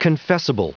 Prononciation du mot confessable en anglais (fichier audio)
Prononciation du mot : confessable